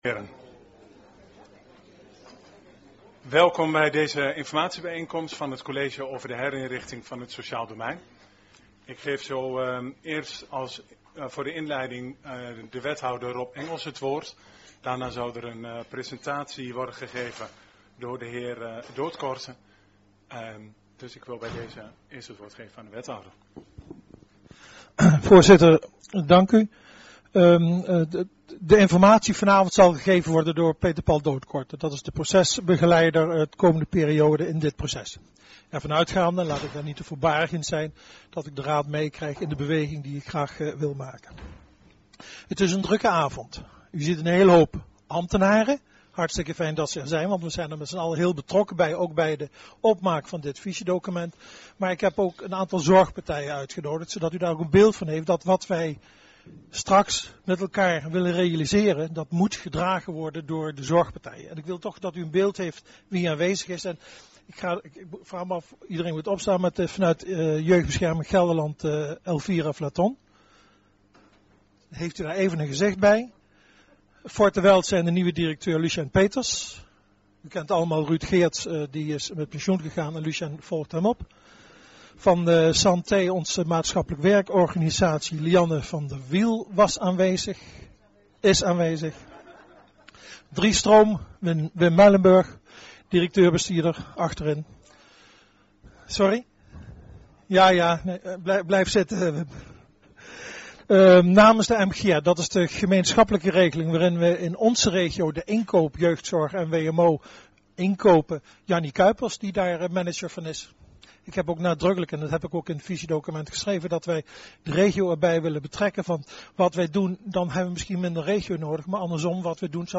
Locatie gemeentehuis Elst Toelichting Informatiebijeenkomst van College over Herinrichting sociaal domein Agenda documenten 18-09-18 Opname 2.